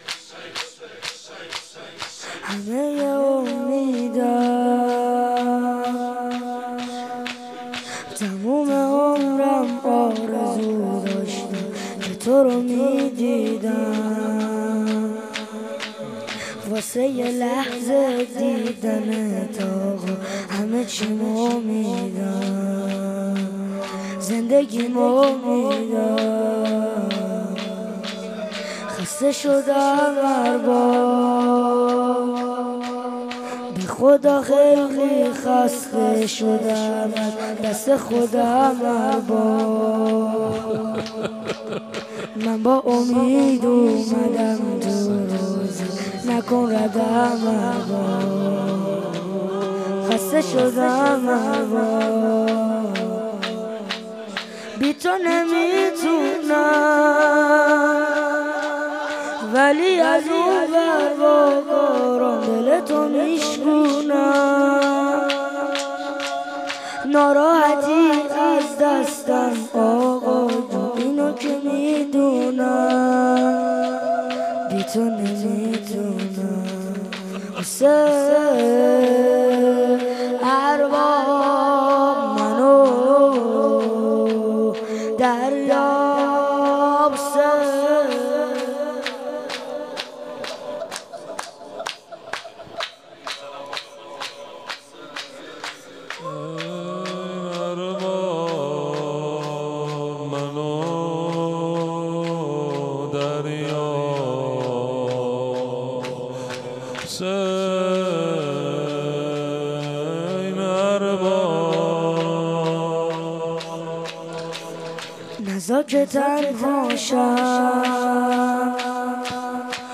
شور | همه ی امیدم